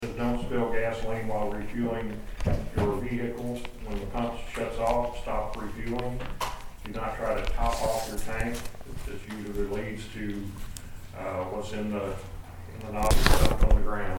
Ward III Councilman Dan Brandt gave the Storm Water Tip of the Month during the meeting of the Marshall City Council on Monday, June 7.